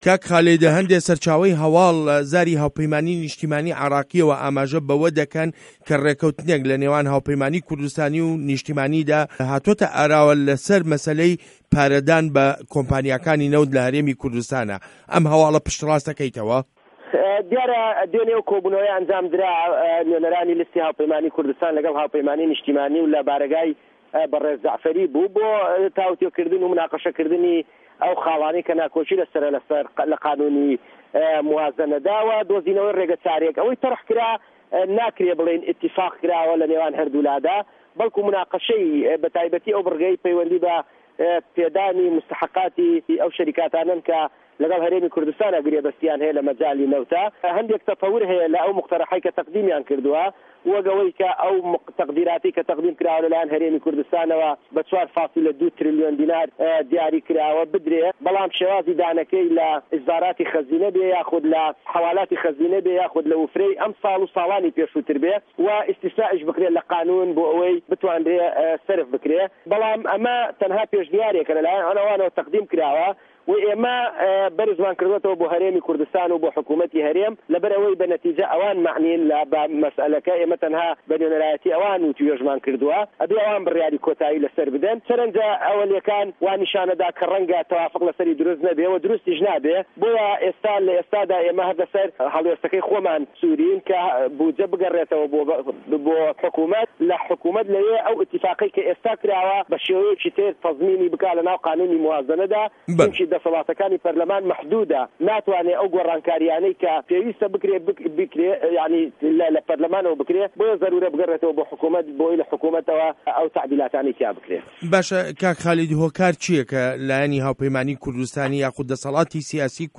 وتووێژ له‌گه‌ڵ خالید شوانی